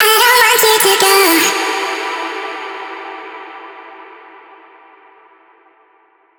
VR_vox_hit_dontgo_E.wav